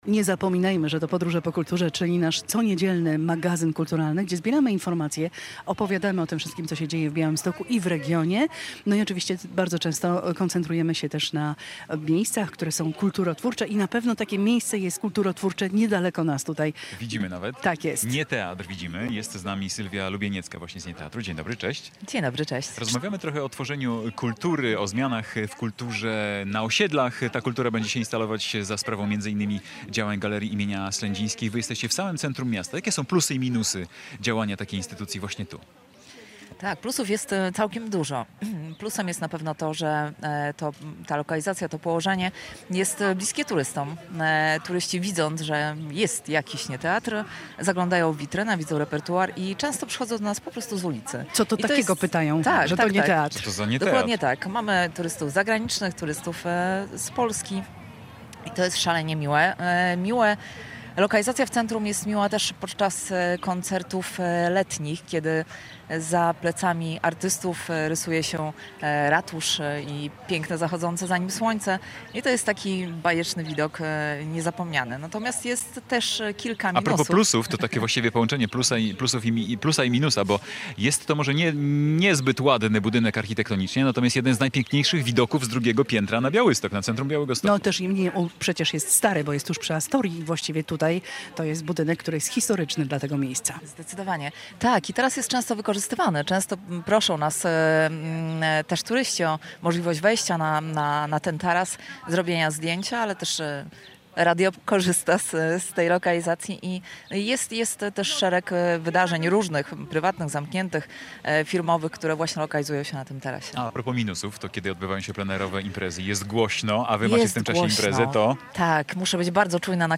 Polskie Radio Białystok w niedzielę otworzyło mobilne studio przy Ratuszu.